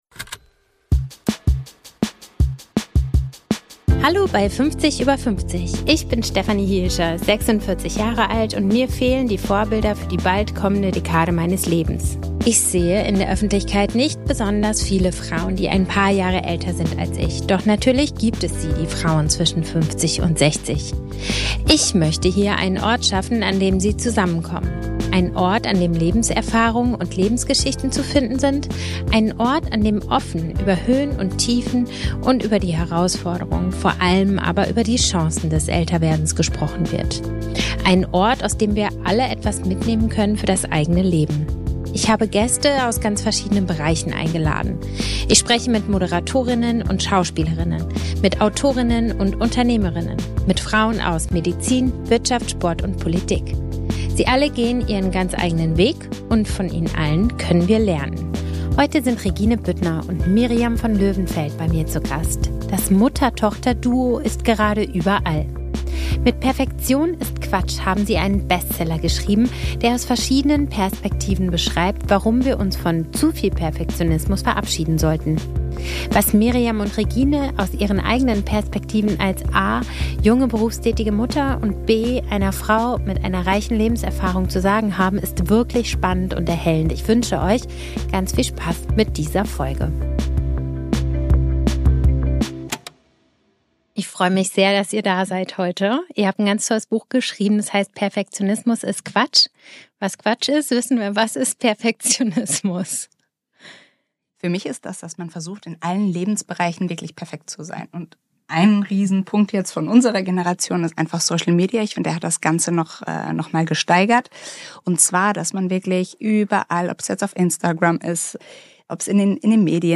Ich spreche mit Moderatorinnen und Schauspielerinnen, mit Autorinnen und Unternehmerinnen, mit Frauen aus Medizin, Sport, Wirtschaft und Politik.